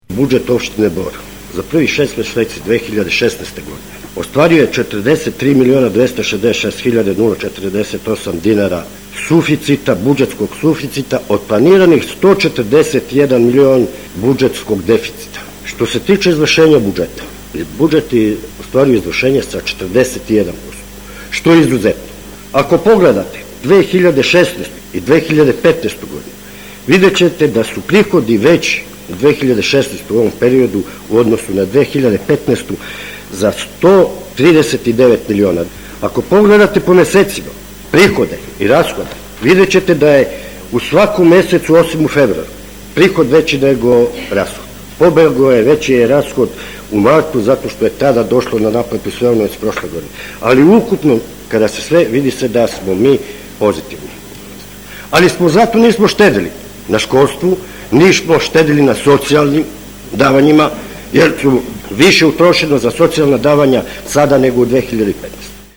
Budžet opštine Bor za prvih šest meseci ove godine ostvario je suficit od 43,2 miliona dinara, izjavio je na sednici Skupštine opštine predsednik opštine Bor Živorad Petrović.
Predsednk-opstine-Bor-Zivorad-Petrovic.mp3